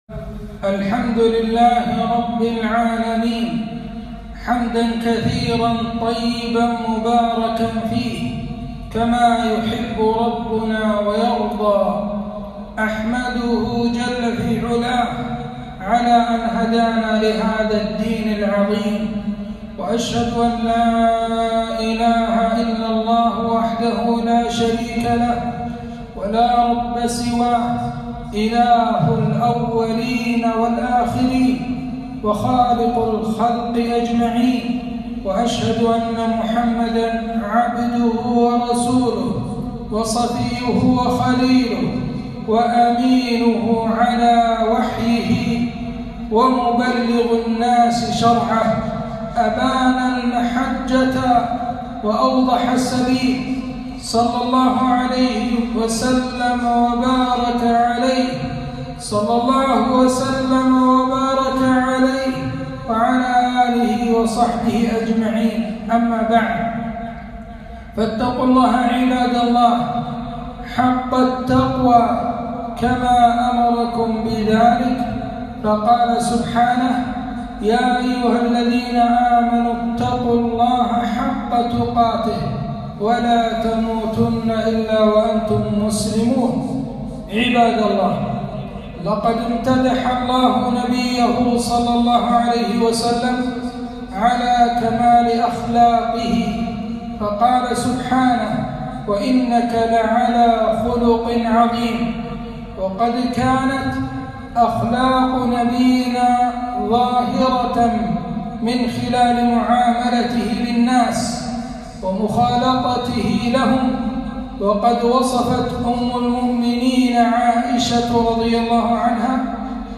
خطبة - مكانة العمل وآدابه في الإسلام